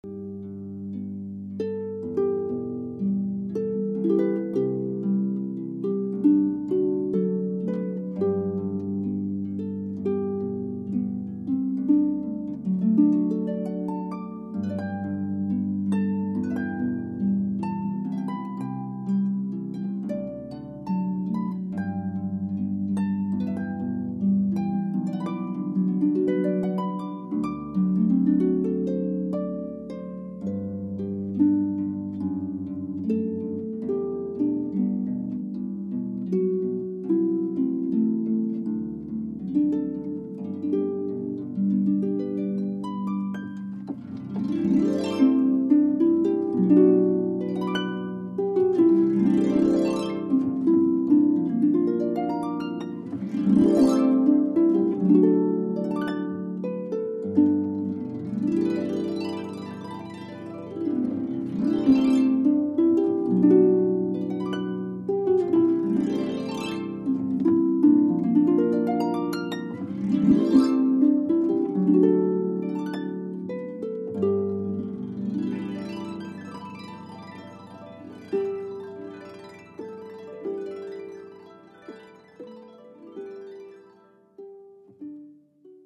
Harp Song: